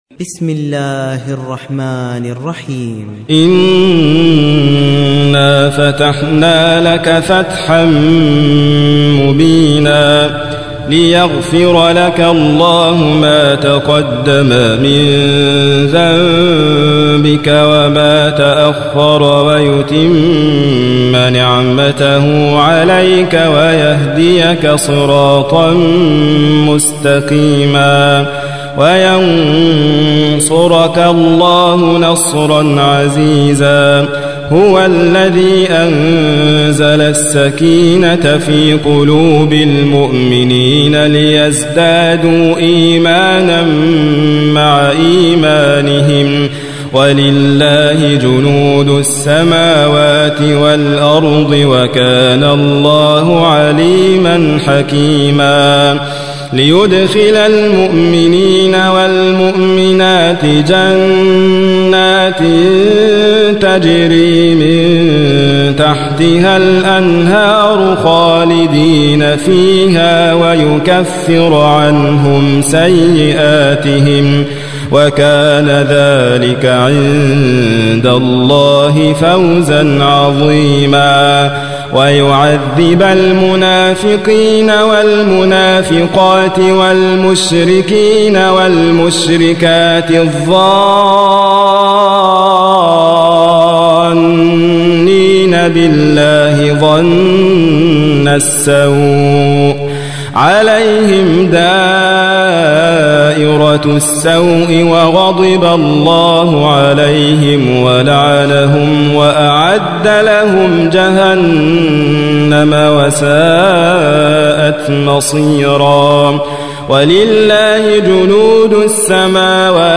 48. سورة الفتح / القارئ